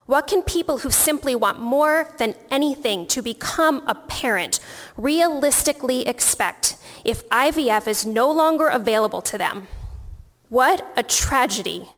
Representative Heather Matson of Ankeny says her two children are the result of fertility treatments.